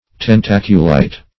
Search Result for " tentaculite" : The Collaborative International Dictionary of English v.0.48: Tentaculite \Ten*tac"u*lite\, n. (Paleon.)